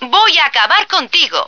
flak_m/sounds/female2/est/F2diebitch.ogg at 602a89cc682bb6abb8a4c4c5544b4943a46f4bd3